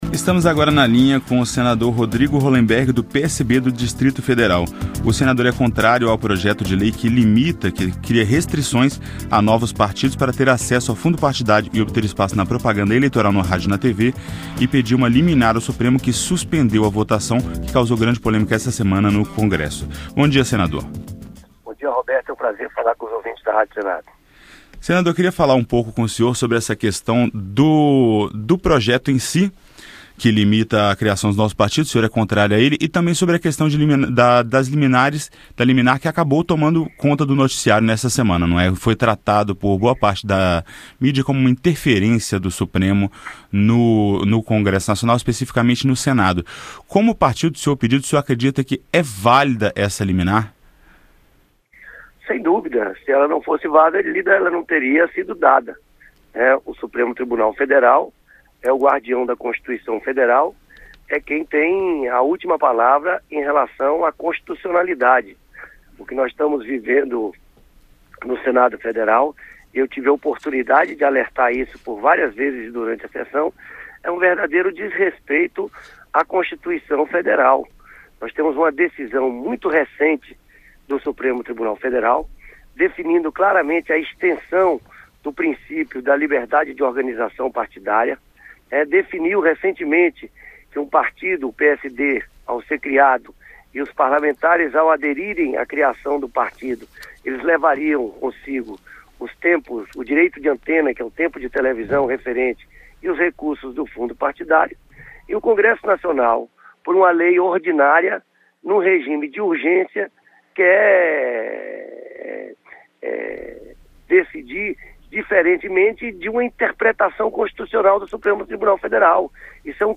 Entrevista com o senador Rodrigo Rollemberg (PSB-DF).